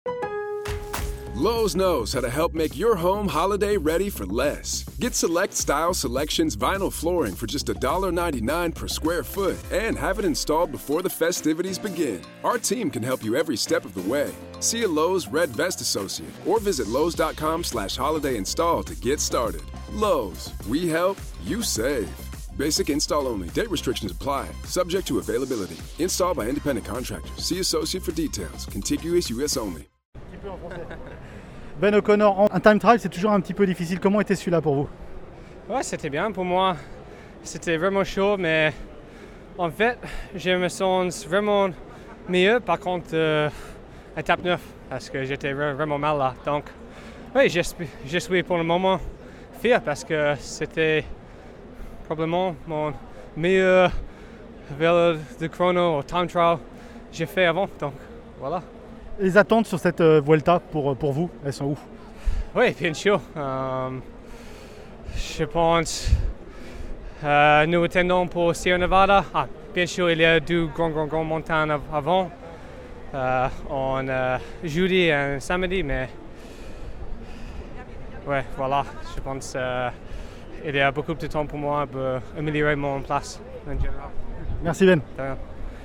Entretien avec Ben O’Connor sur La Vuelta sur le time trial, le chrono de la 10e étape et sur les étapes à venir